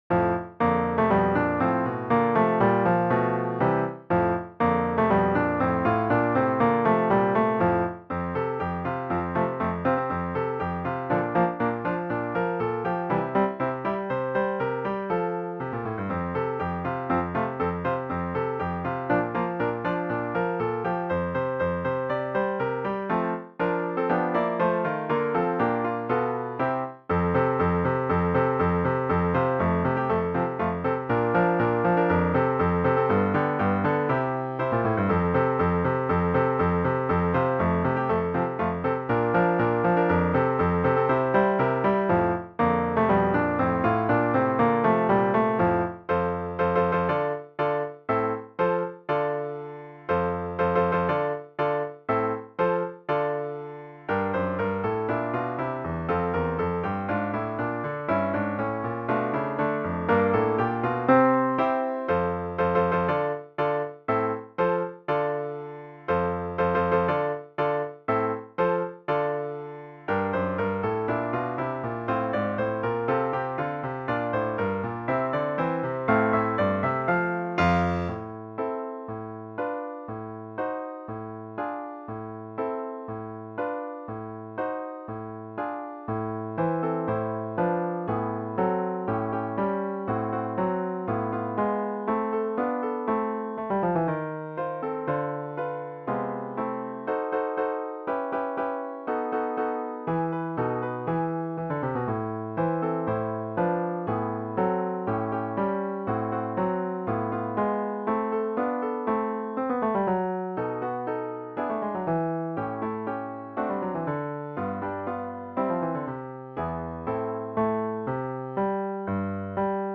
four guitars